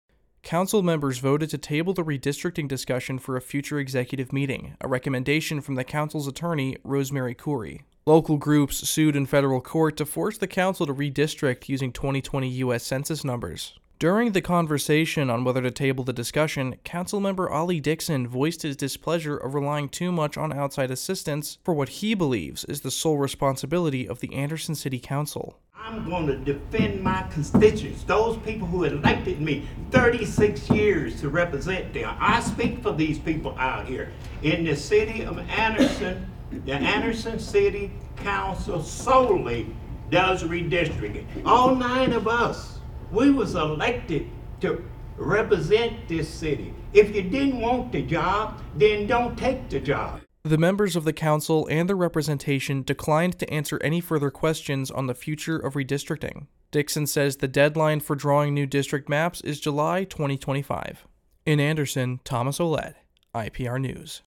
Anderson City Councilman Ollie Dixon speaks on redistricting.